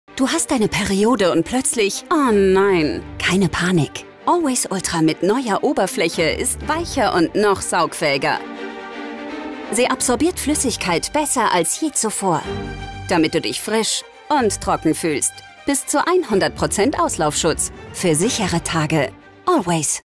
dunkel, sonor, souverän, sehr variabel
Mittel minus (25-45)
Commercial (Werbung), Off